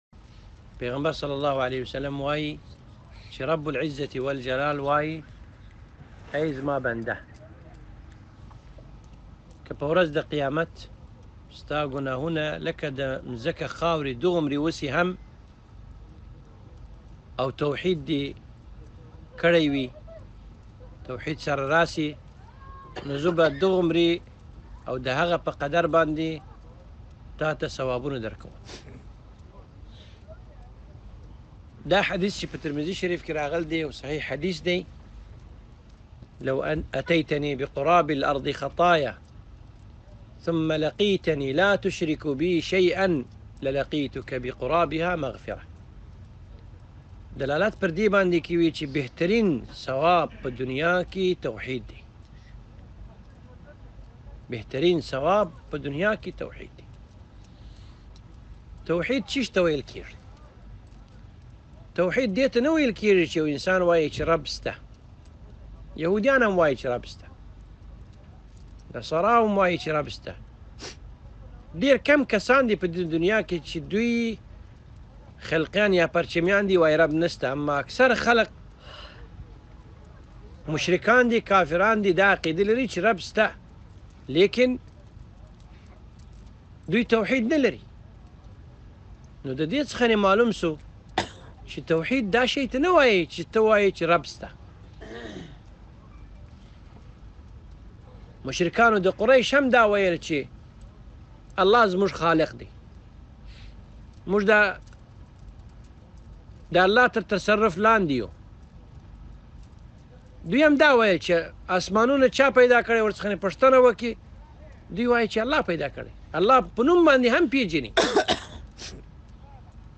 محاضرة باللغة الأفغانية بشتو (توحيد څه ته ویلکیږی)